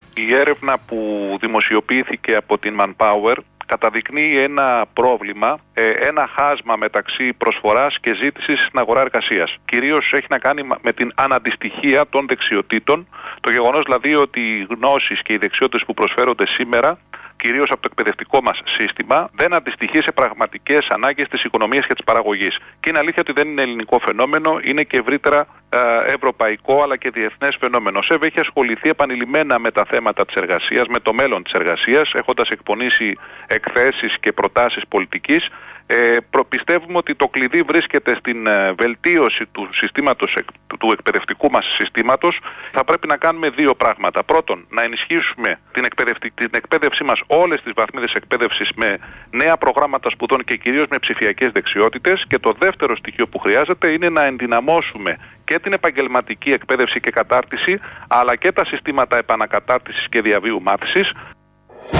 Συνέντευξη
στο Κεντρικό Δελτίο Ειδήσεων του Ρ/Σ REAL FM